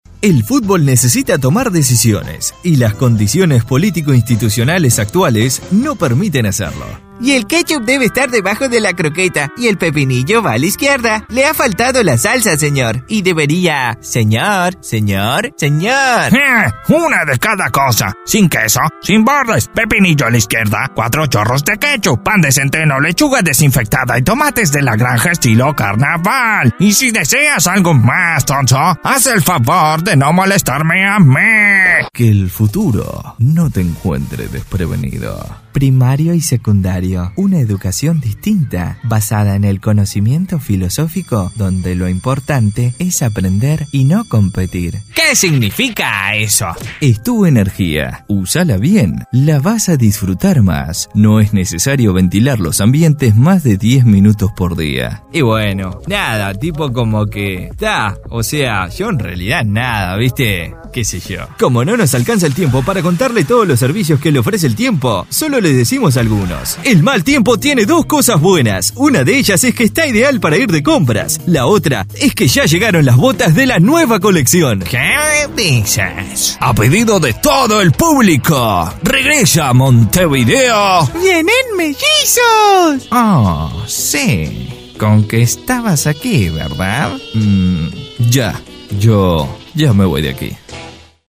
LOCUTOR Comercial
spanisch Südamerika
Sprechprobe: Werbung (Muttersprache):